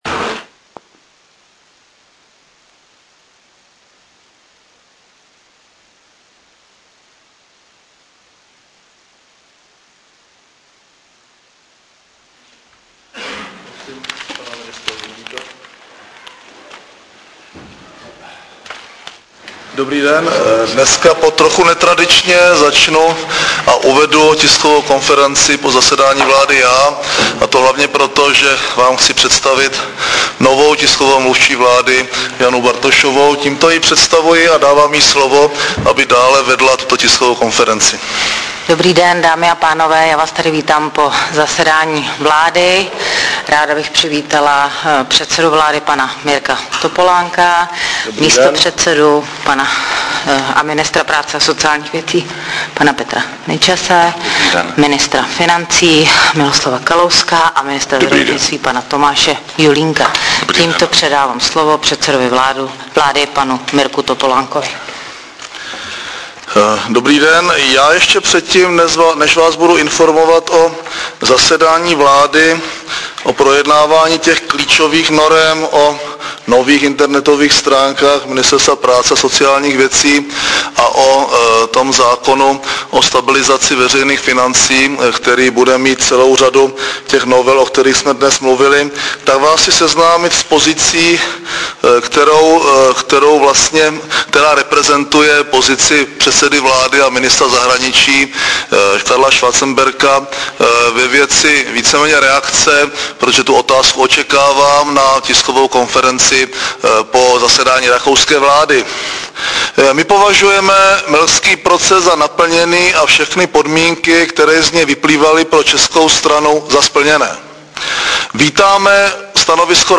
Tisková konference po zasedání vlády ve středu 16.5.2007